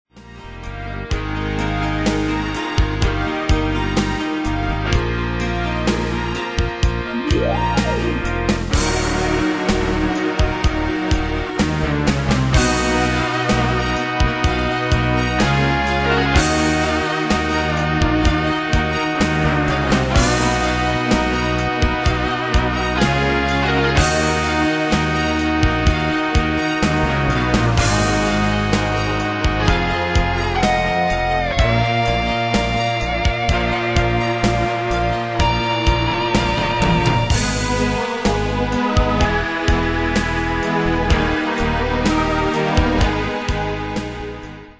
Demos Midi Audio
Style: PopFranco Année/Year: 2001 Tempo: 63 Durée/Time: 4.20
Danse/Dance: SlowRock Cat Id.
Pro Backing Tracks